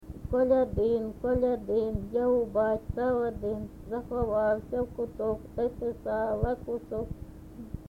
ЖанрКолядки
Місце записус. Хрестівка, Горлівський район, Донецька обл., Україна, Слобожанщина